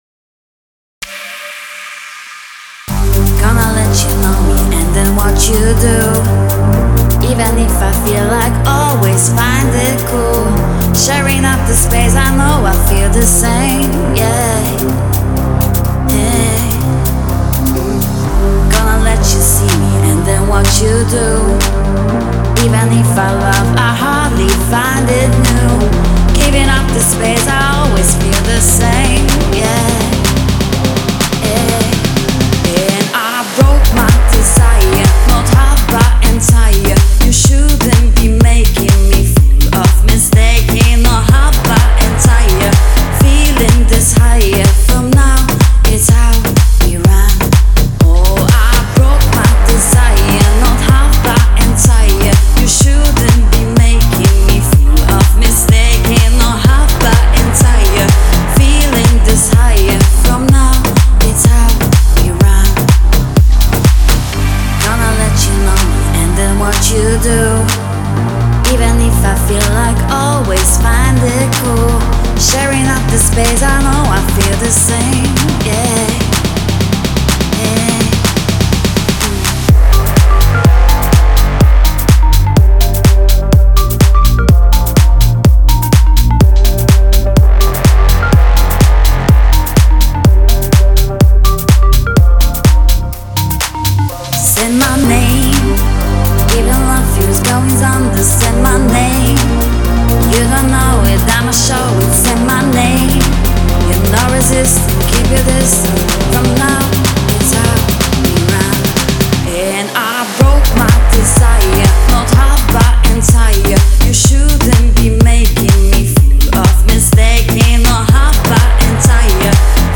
это энергичная электронная композиция в жанре EDM